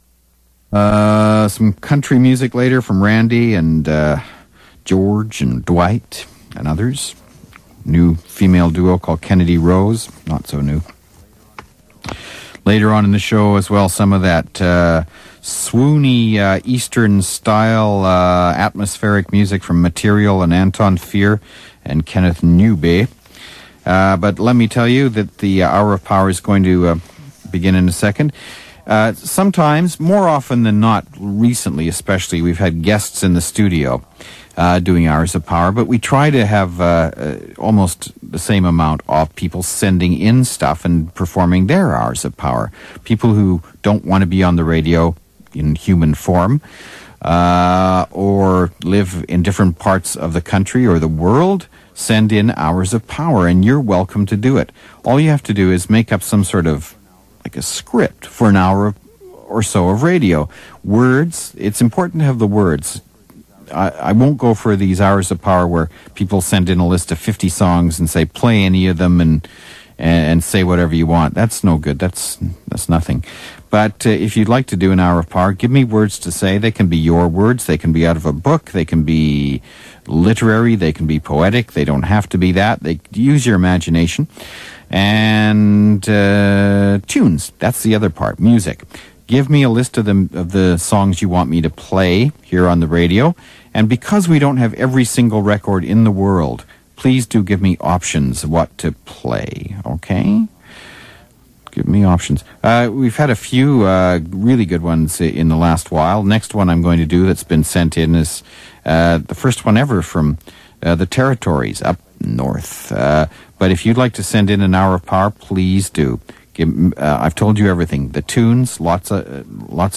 CBC Radio One, Poems & Tunes